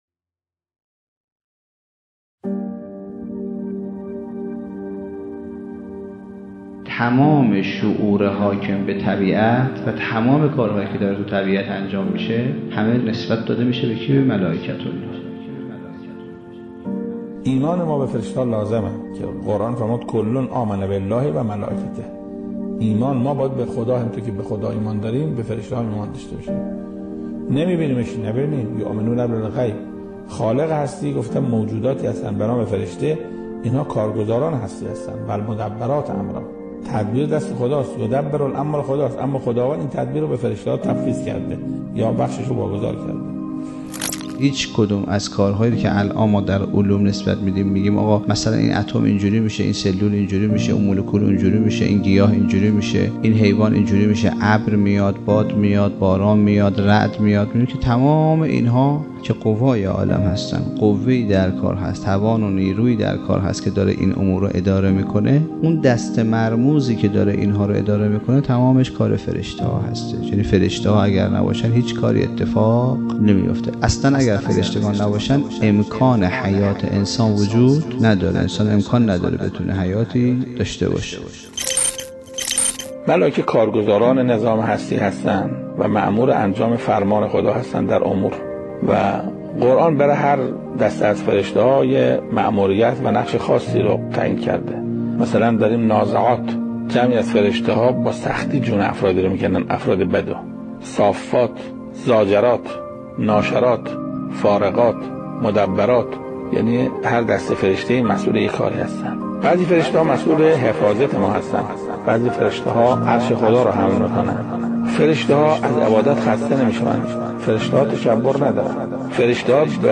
سخنرانی انسان شناسى